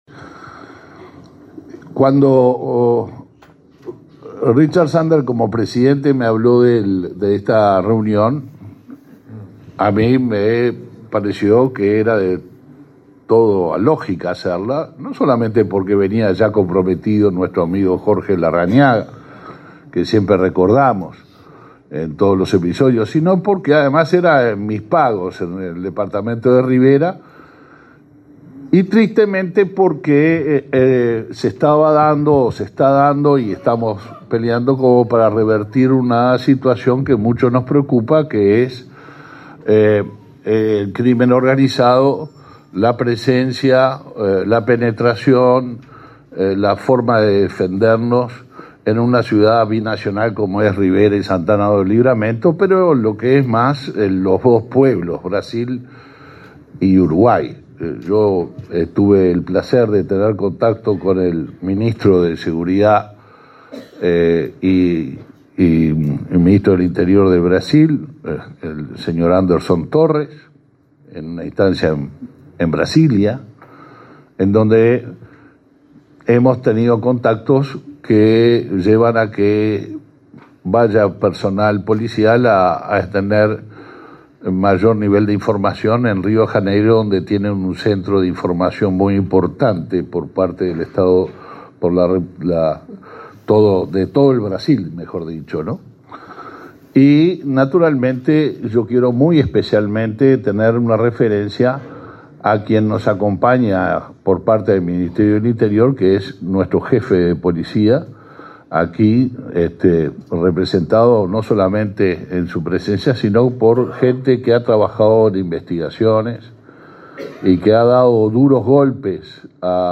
Palabras de autoridades en encuentro binacional Uruguay-Brasil
Palabras de autoridades en encuentro binacional Uruguay-Brasil 30/08/2022 Compartir Facebook X Copiar enlace WhatsApp LinkedIn El ministro del Interior, Luis Alberto Heber; el canciller, Francisco Bustillo, y el ministro de Defensa Nacional, Javier García, participaron en Rivera en un encuentro binacional entre Uruguay y Brasil sobre seguridad pública.